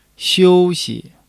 xiu1-xi.mp3